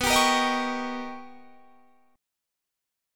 B+M9 Chord
Listen to B+M9 strummed